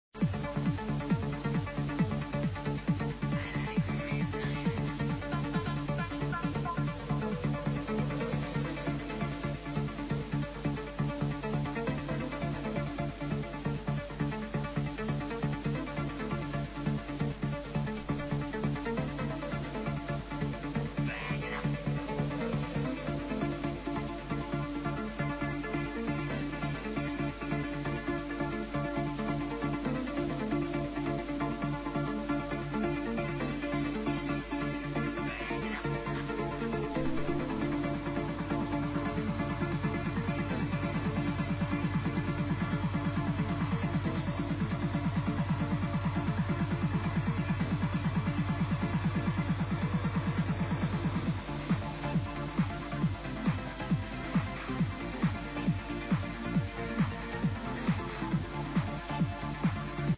the new sample is a really low quality though...